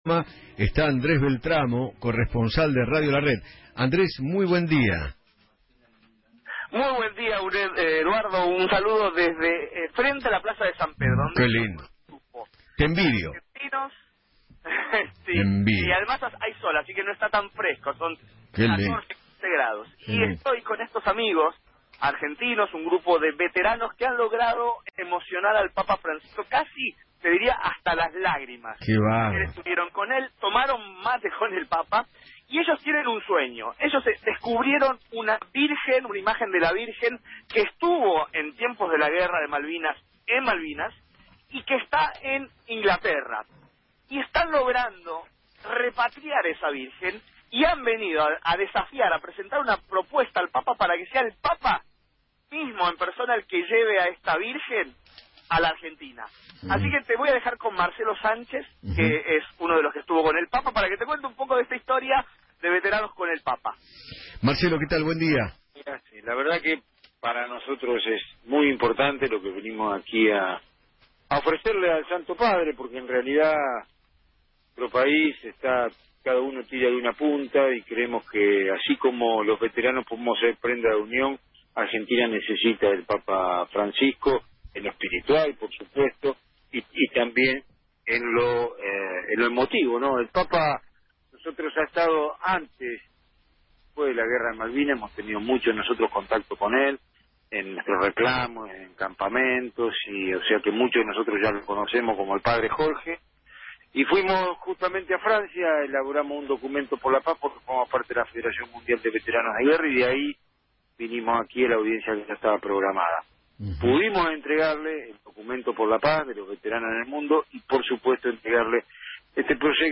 habló en Feinmann 910 y contó su experiencia junto al Papa Francisco